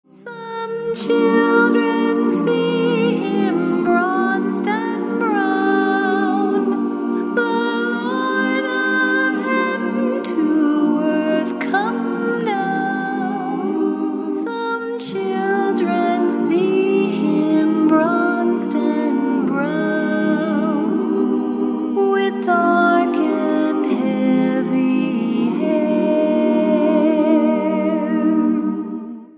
carols in their original form, a cappella.